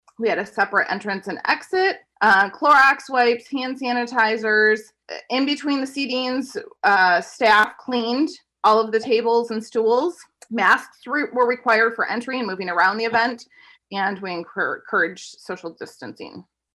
during Monday’s Coldwater City Council meeting